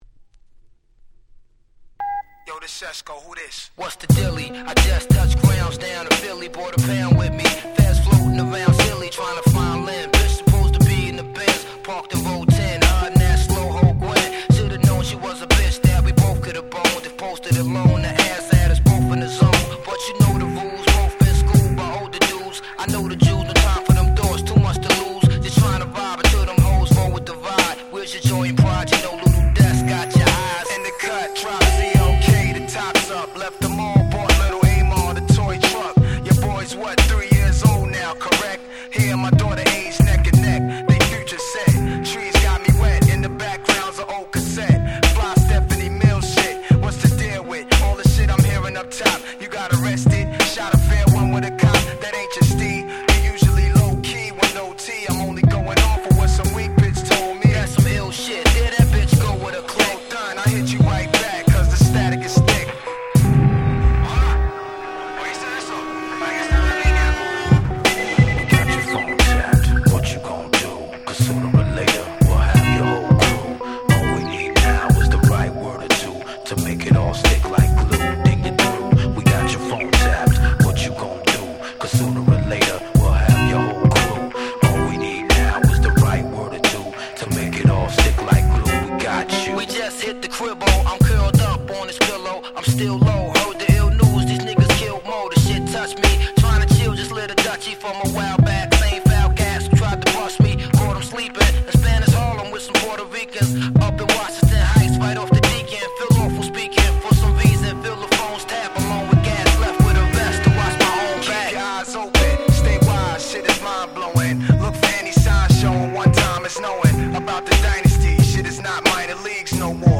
97' Hip Hop Super Classics !!